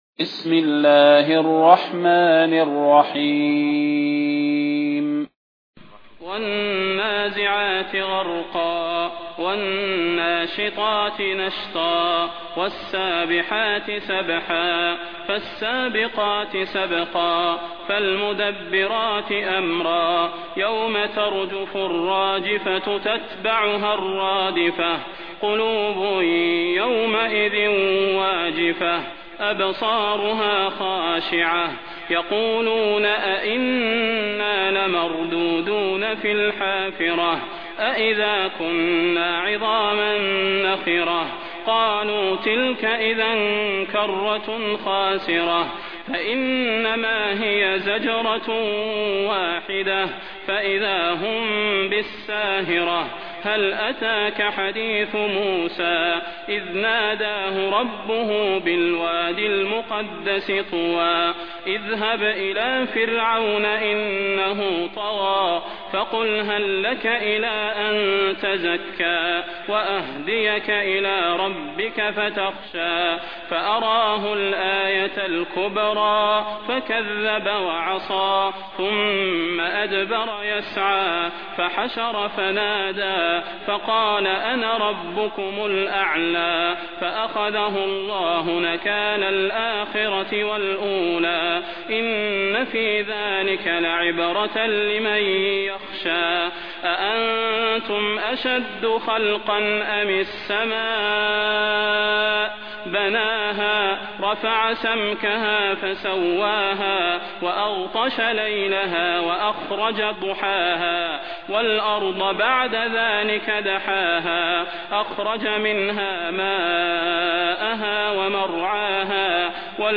المكان: المسجد النبوي الشيخ: فضيلة الشيخ د. صلاح بن محمد البدير فضيلة الشيخ د. صلاح بن محمد البدير النازعات